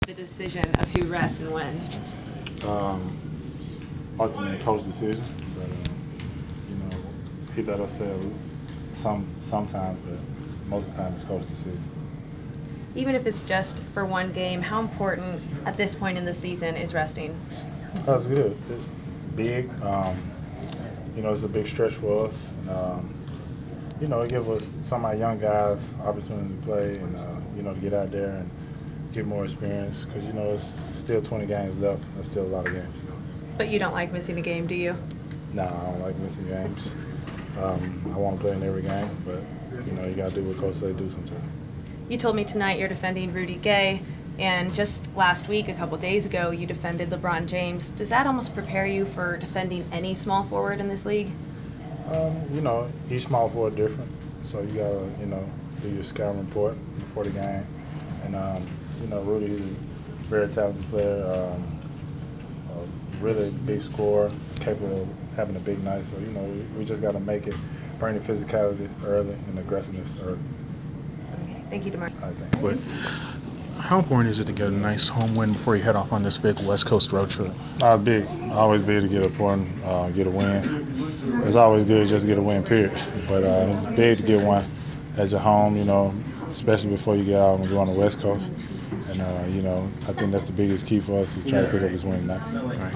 Inside the Inquirer: Pregame presser with Atlanta Hawk DeMarre Carroll (3/9/15)
We attended the pregame presser of Atlanta Hawks’ forward DeMarre Carroll before his team’s home contest against the Sacramento Kings on Mar. 9. Topics included defending Sacramento’s Rudy Gay, resting in the Hawks’ previous game at Philadelphia and importance of getting home win before long road trip.